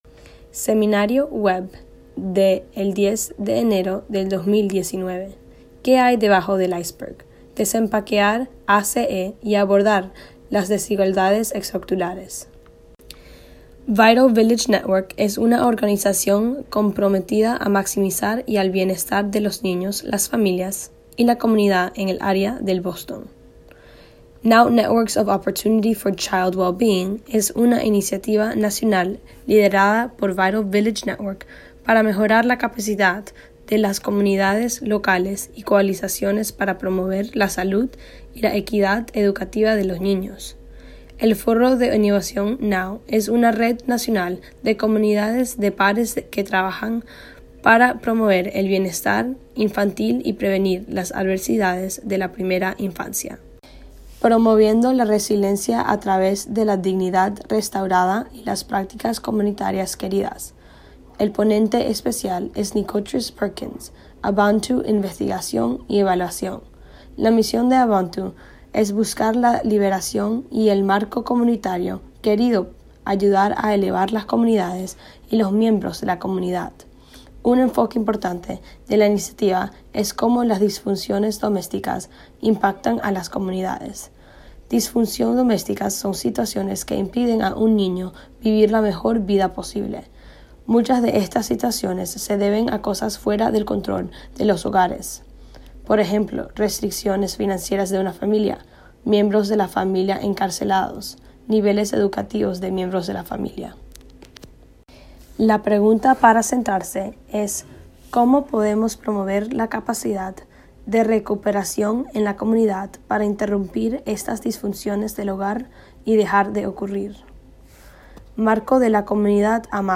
VIRTUAL LEARNING LAB ARCHIVE
Building upon an multi-systems “iceberg” model and Dignity framework, this webinar will explore the multiple layers and systems that contribute to inequities in community experiences and outcomes shaped by early life adversities. Speakers will share how they are unpacking these layers and lift up examples of efforts underway to address these critical issues.